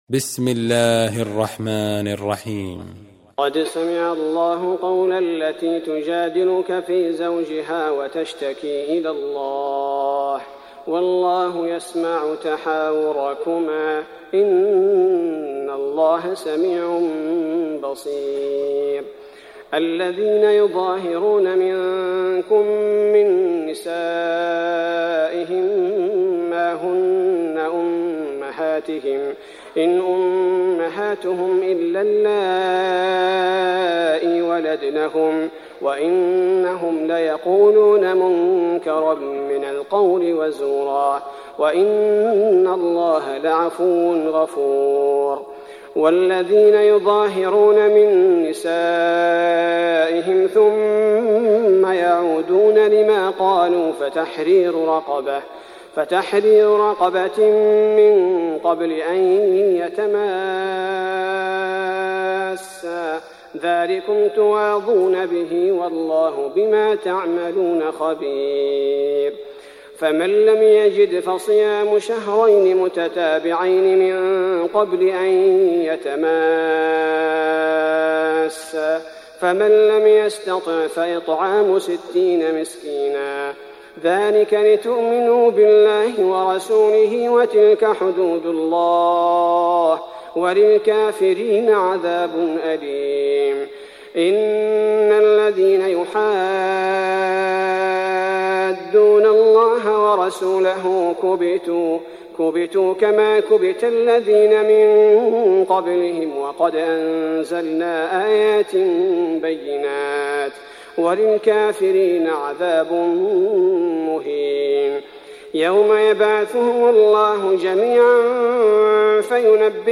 تحميل سورة المجادلة mp3 بصوت عبد الباري الثبيتي برواية حفص عن عاصم, تحميل استماع القرآن الكريم على الجوال mp3 كاملا بروابط مباشرة وسريعة